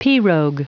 Prononciation du mot pirogue en anglais (fichier audio)
Prononciation du mot : pirogue